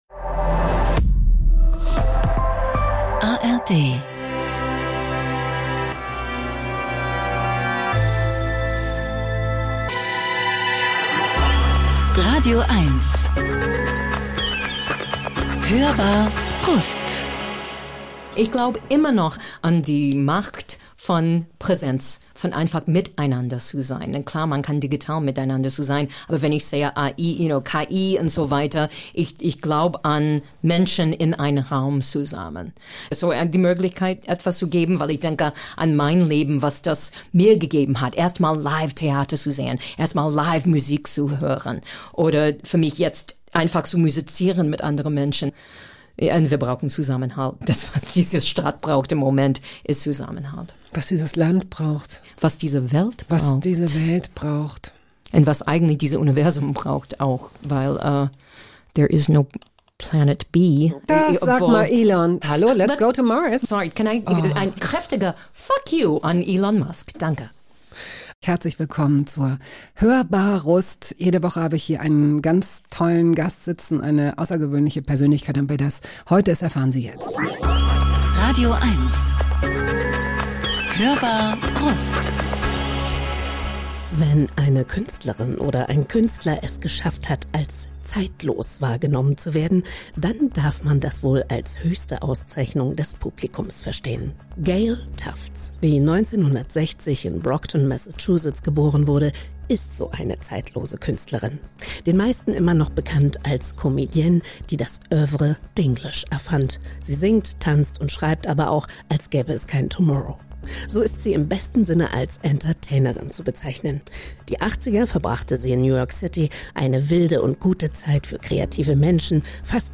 zu Gast: Gayle Tufts, 64, Entertainerin & Sängerin